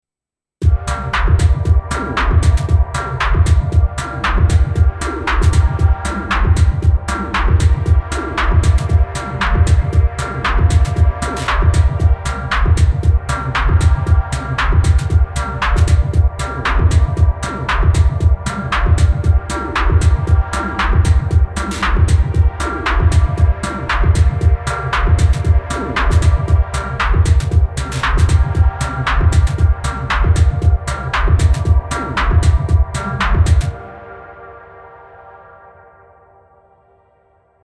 I went for quantity over quality this time :sweat_smile: This is all over the place, but quite firmly in the melodic/tonal camp.